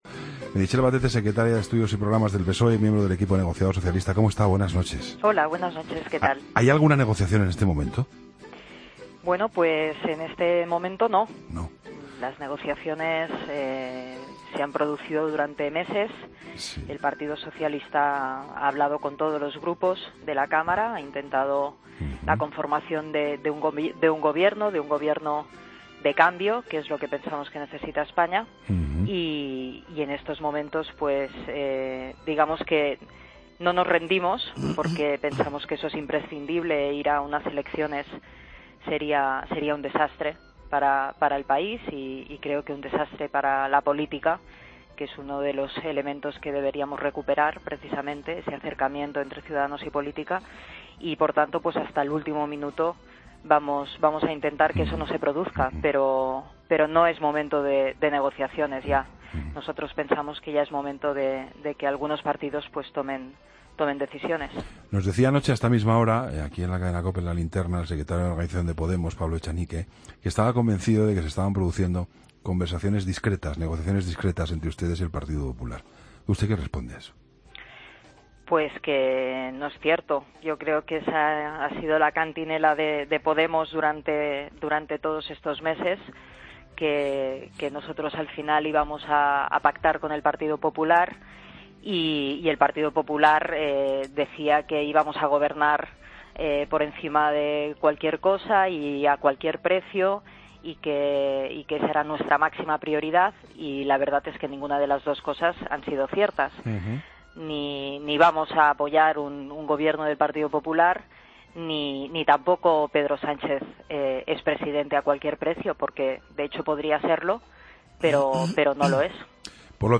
AUDIO: Entrevista a Meritxell Batet, secretaria de Estudios y Programas del PSOE y miembro del equipo negociador socialista, en 'La Linterna'